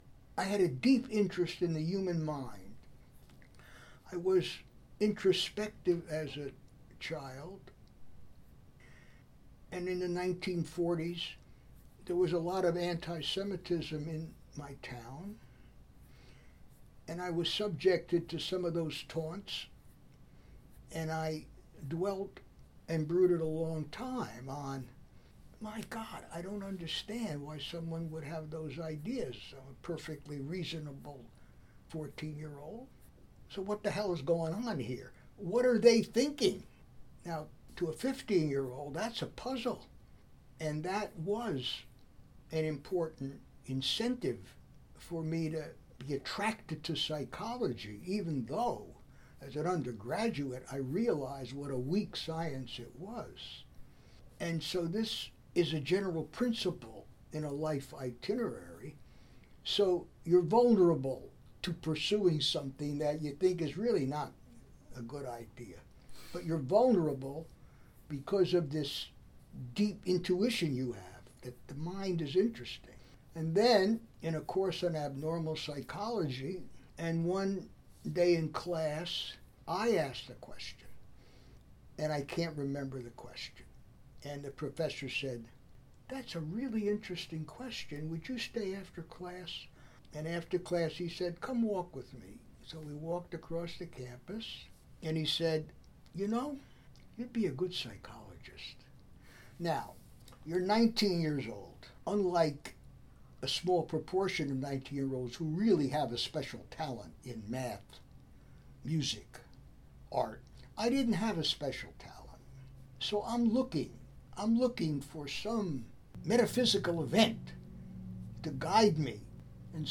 Here’s how Dr. Kagan describes his decision to study Psychology (or how it may have been fated to select him?):
My conversation with Dr. Kagan traversed a lot of his early experiences, including much of his primary research.